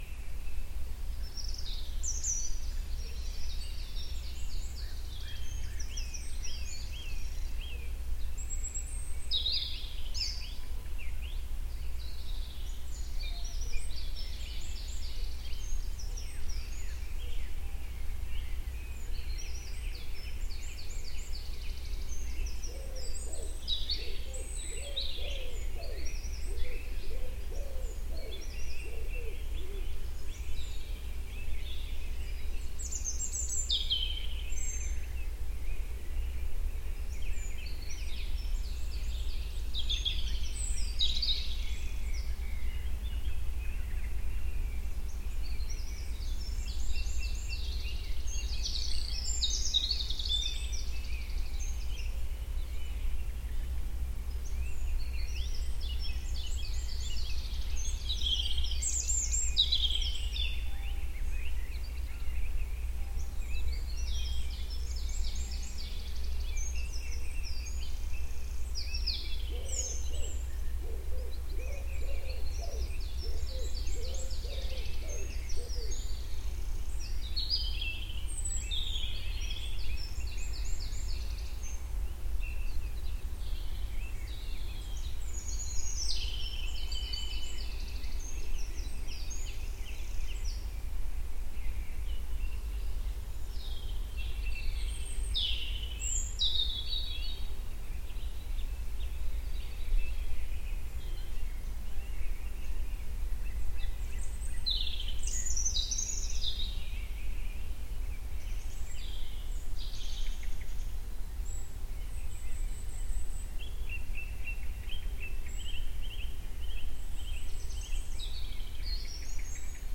Здесь вы найдете успокаивающие композиции из шума прибоя, пения цикад и легкого ветра — идеальный фон для отдыха, работы или сна.
Пение птиц на вечернем закате